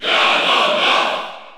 Crowd cheers (SSBU) You cannot overwrite this file.
Ganondorf_Cheer_French_PAL_SSBU.ogg